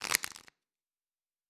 Cards Shuffle 2_02.wav